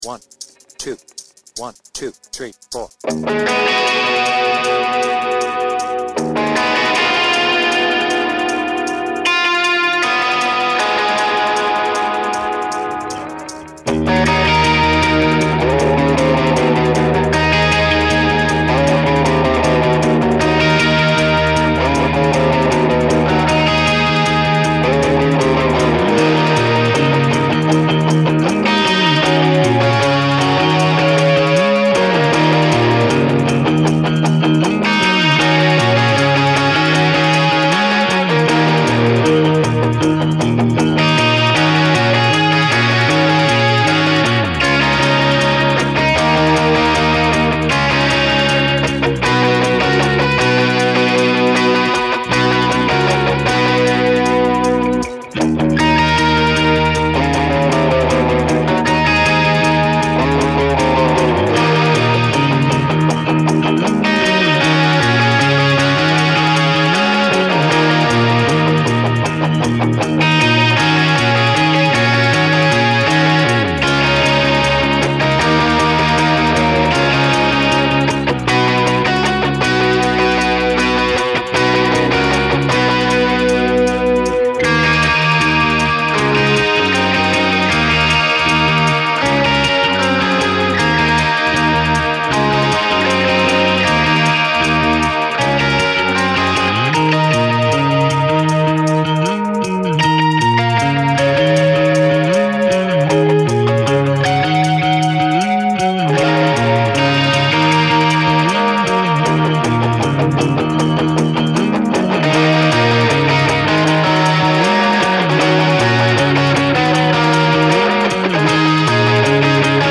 BPM : 156
Tuning : Eb
Without vocals
Based on the studio version